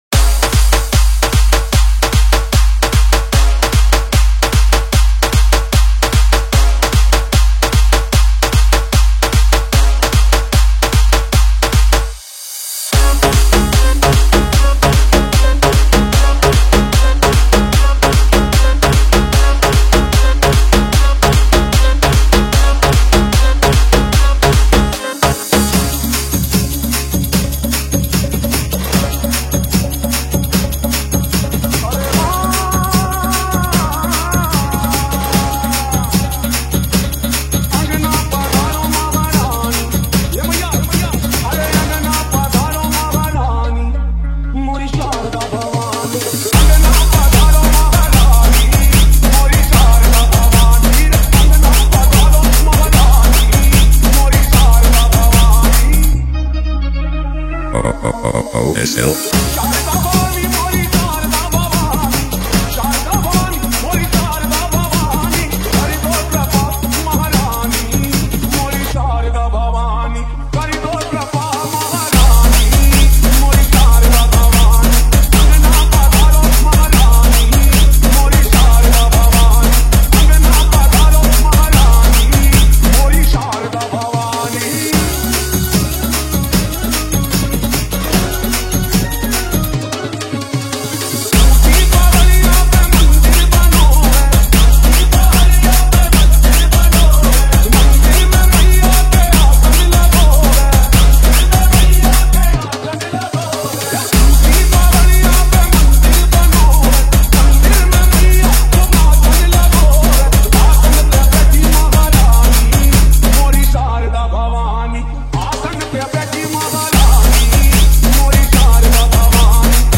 150 Bpm Remix